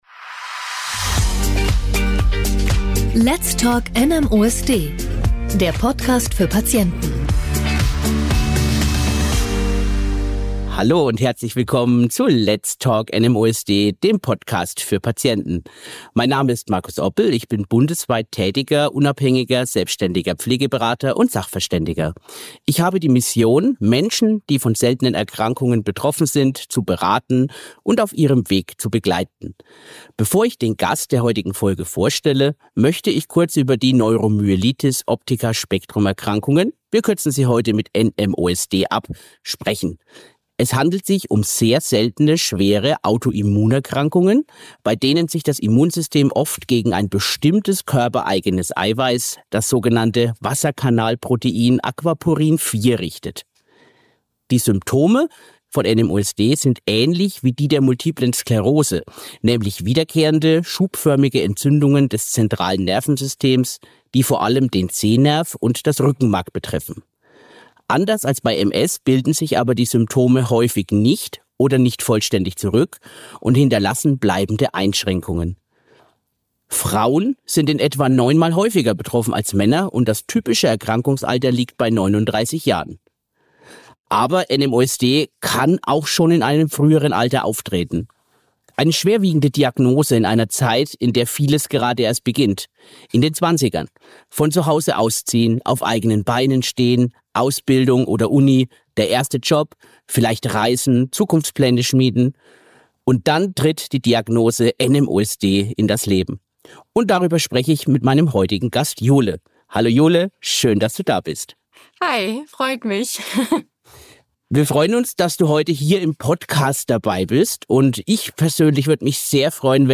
Ein Gespräch über eine große Veränderung im Leben – und darüber, wie man trotz allem den eigenen Weg findet.